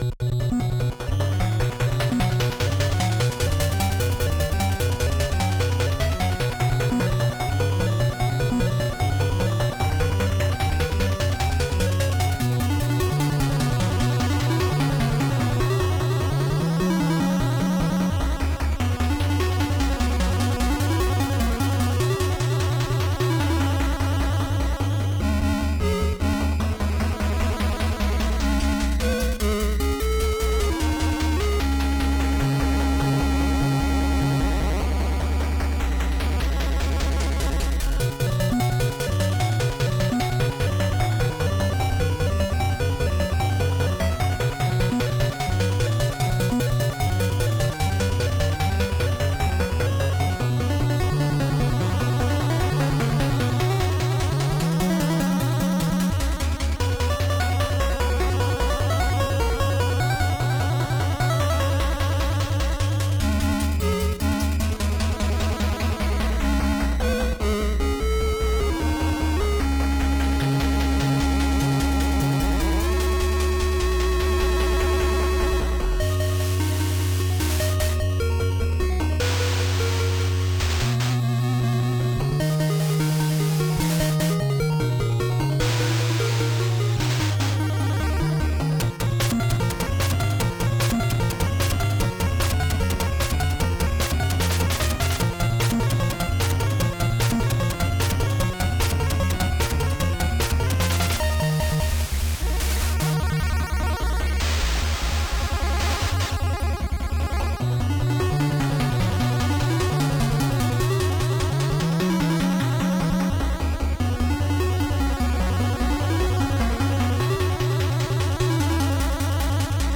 A chiptune . . .